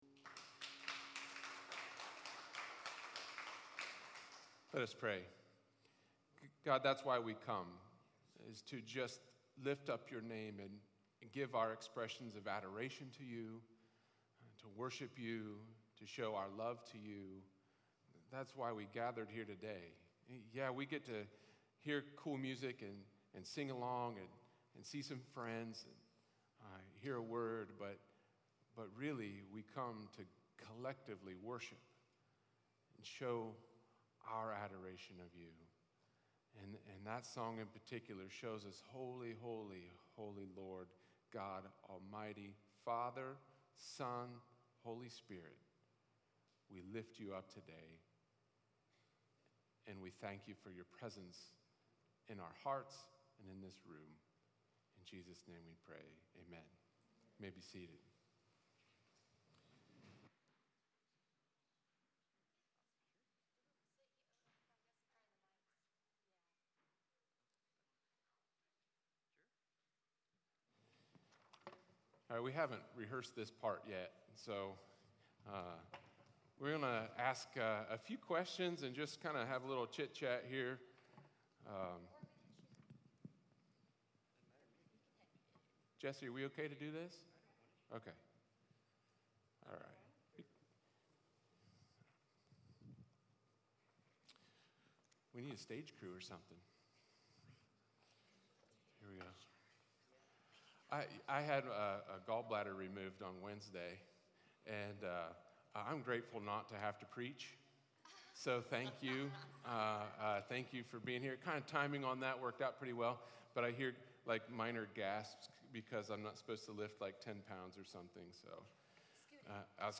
Nashville recording artists Cherry Avenue led worship, played some original songs and told their story of God's redemption on their lives, plus a bonus to hear the story behind the songs and how to be a Christian in a secular world.